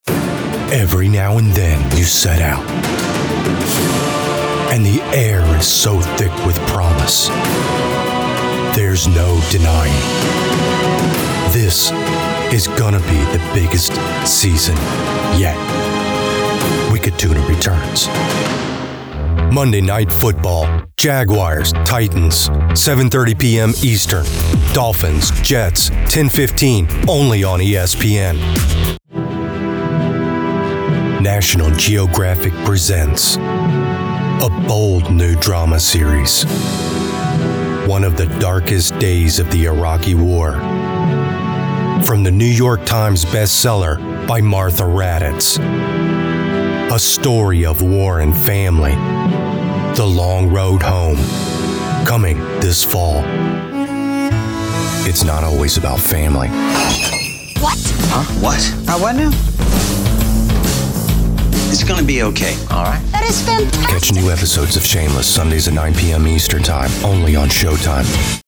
southern us
standard us
authoritative
gravitas
professional home studio
trailer_demo_mixdown_final.mp3